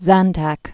(zăntăk)